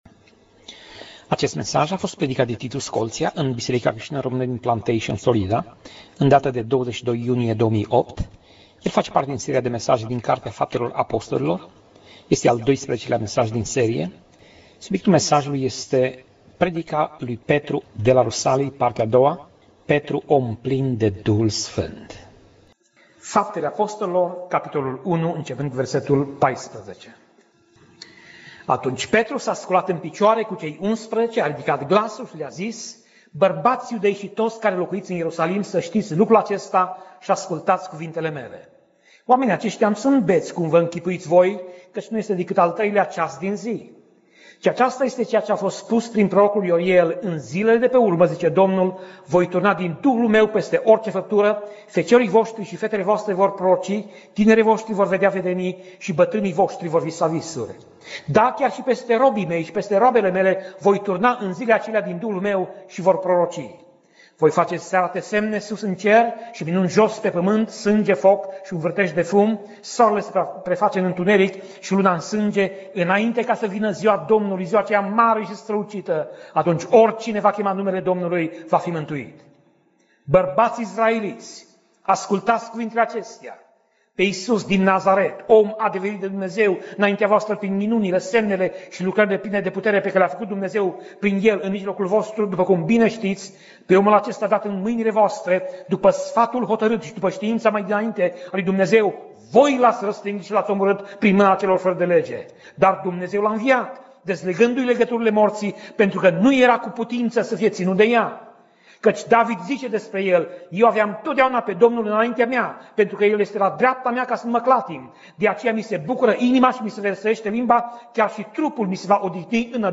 Pasaj Biblie: Faptele Apostolilor 2:14 - Faptele Apostolilor 2:40 Tip Mesaj: Predica